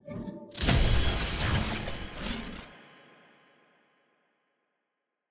sfx-exalted-chase-s-tier-card-vfx.ogg